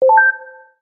На этой странице собраны звуки кнопок меню — короткие и четкие аудиофрагменты, подходящие для приложений, сайтов и игр.
Выбор кнопки в меню альтернативный вариант